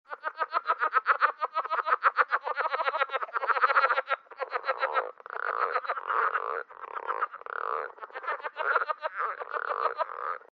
Southern Leopard Frog
Males have paired vocal sacs resembling small balloons on either side of their throats when they’re calling.
The southern leopard frog's sound resembles a short, chuckle-like, guttural trill followed by two or three "clucks." Some calls can sound like rubbing a hand back and forth over a rubber balloon.
southern-leopard-frog-call.mp3